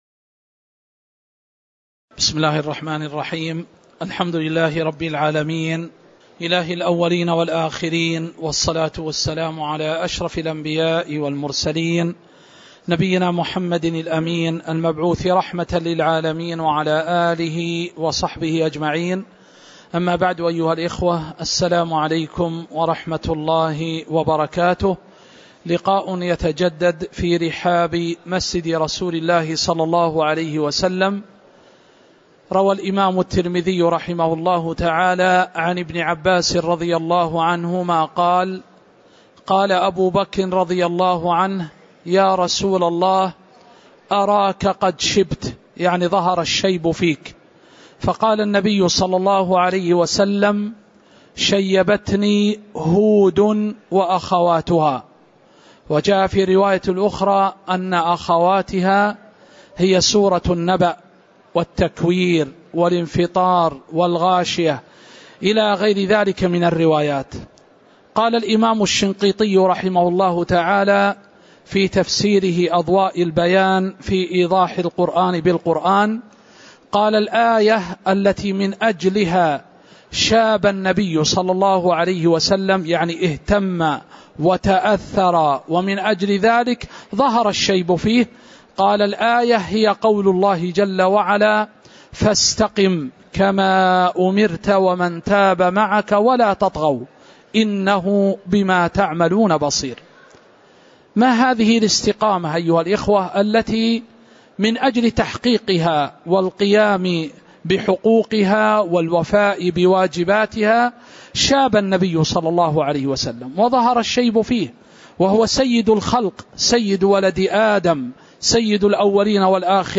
تاريخ النشر ١٧ ذو الحجة ١٤٤٠ هـ المكان: المسجد النبوي الشيخ